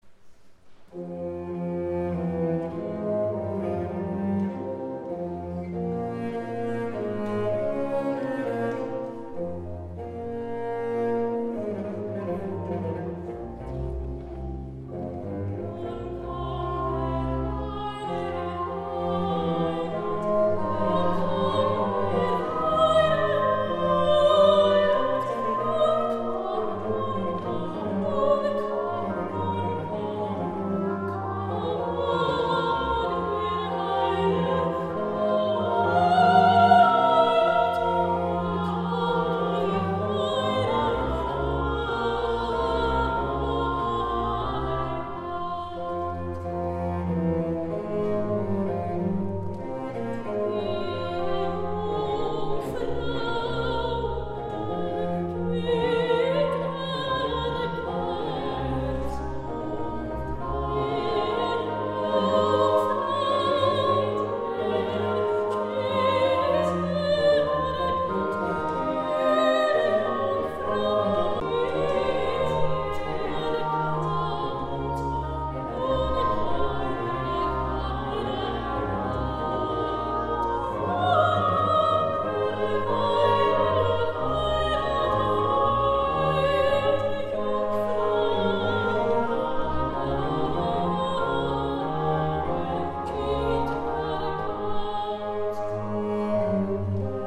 Mezzosopran
Sopranistin